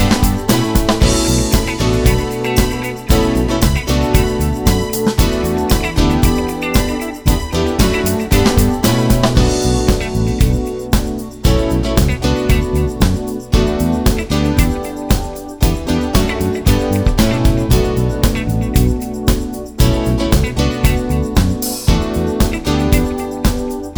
Live - no Backing Vocals Pop (1980s) 5:12 Buy £1.50